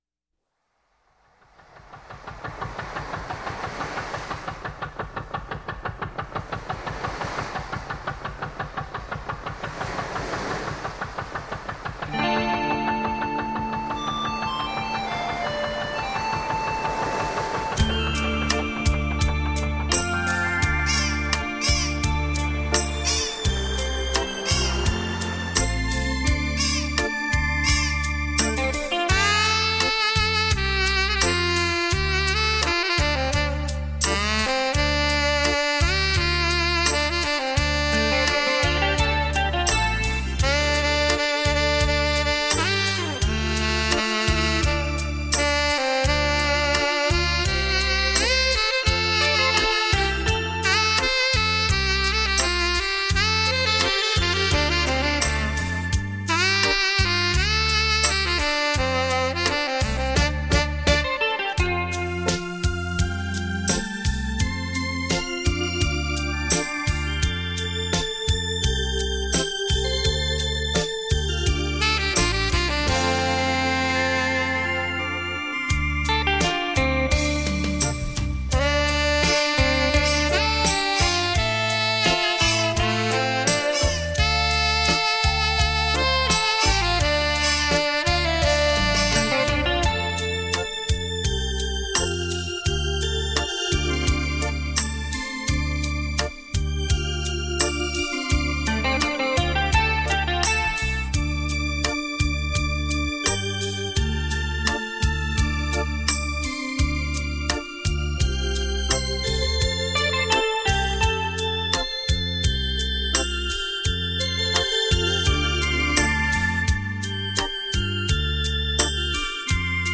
雷射版[音响测试带] 现场演奏